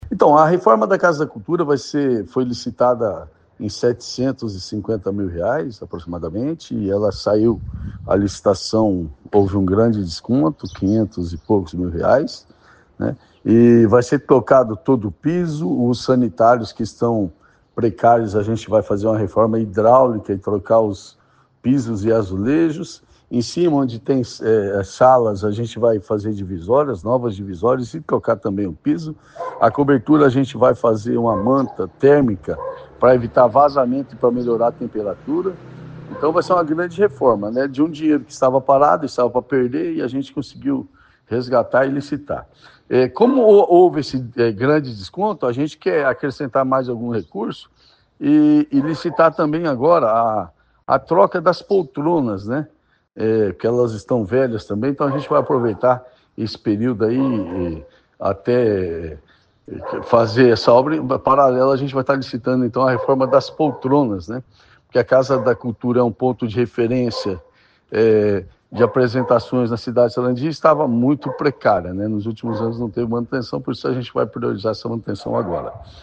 Ouça o que diz o prefeito: